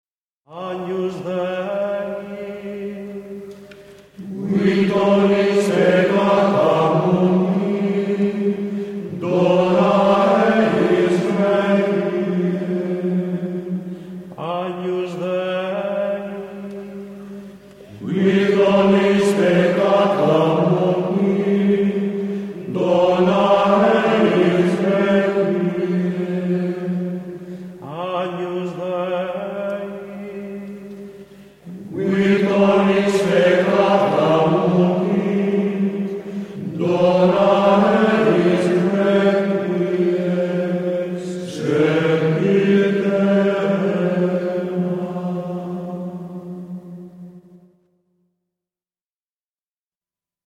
Gregorián zene